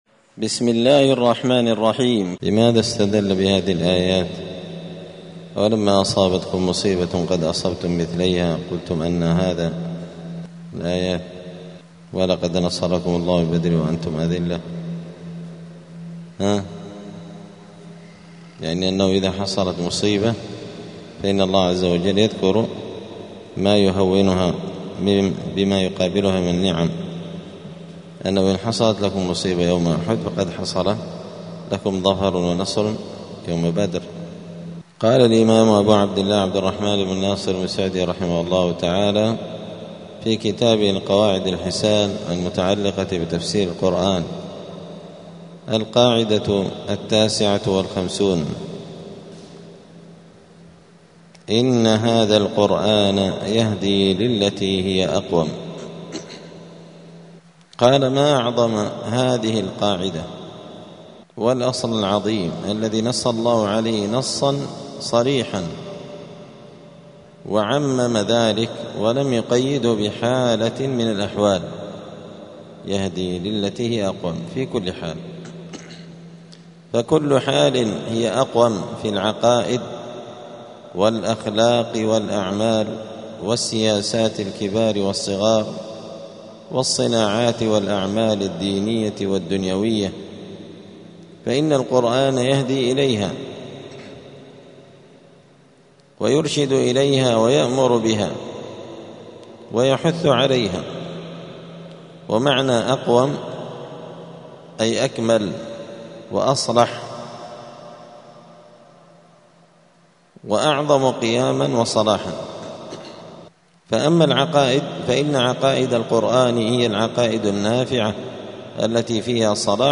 دار الحديث السلفية بمسجد الفرقان قشن المهرة اليمن
70الدرس-السبعون-من-كتاب-القواعد-الحسان.mp3